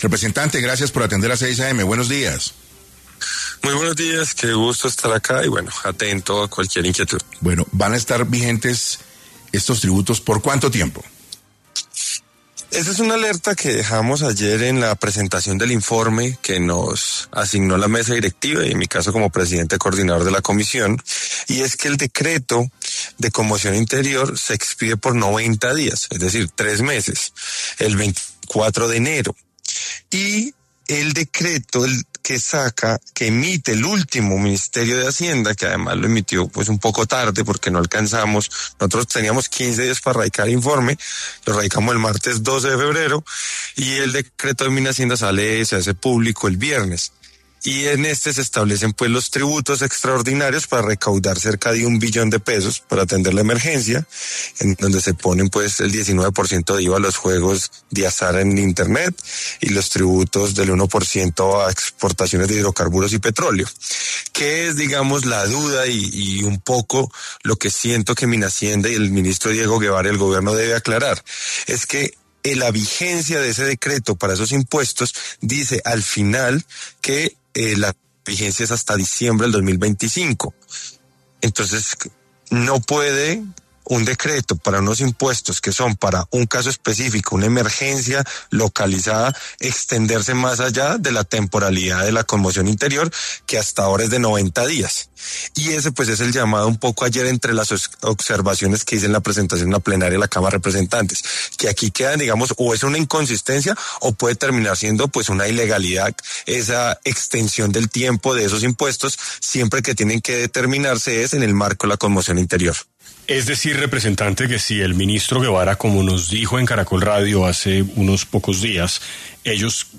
En 6AM de Caracol Radio estuvo el representante ante la Cámara, Duvalier Sánchez, para hablar sobre quién vigilará que los decretos de conmoción interior no superen los 90 días.